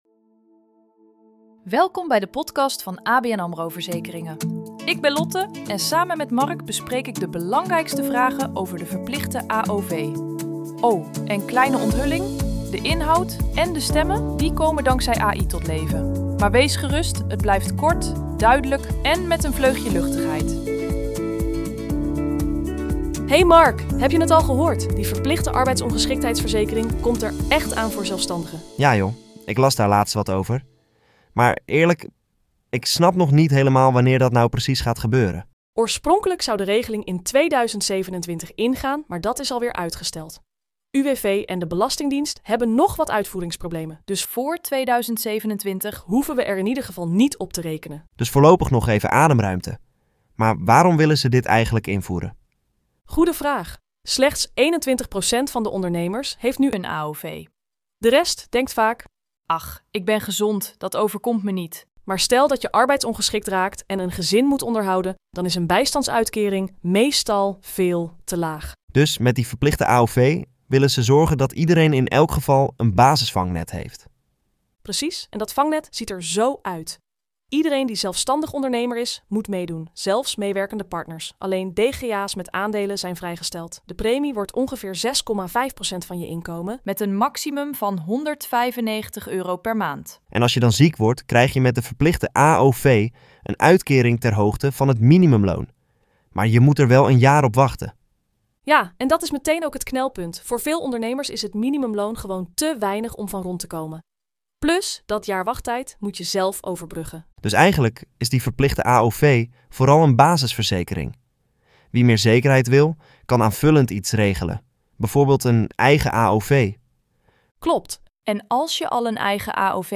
En we gaan mee met onze tijd: deze podcast is met AI gemaakt.